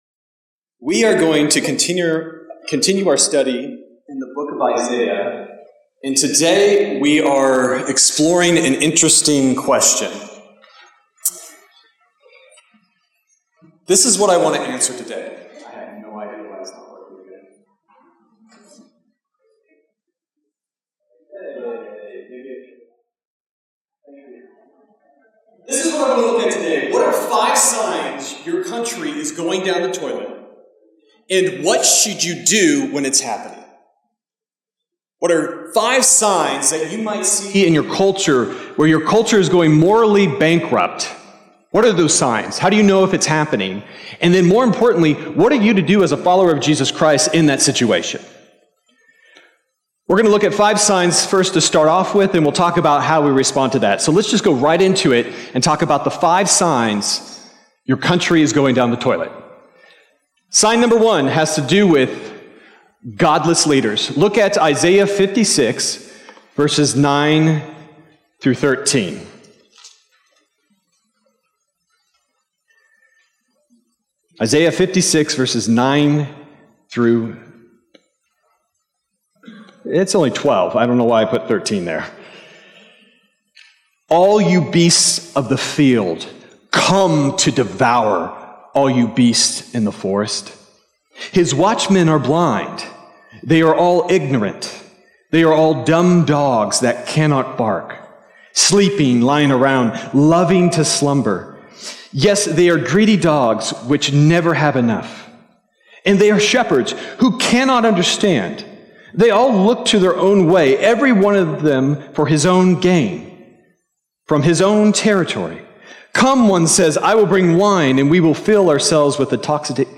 Sermon Detail
January_28th_Sermon_Audio.mp3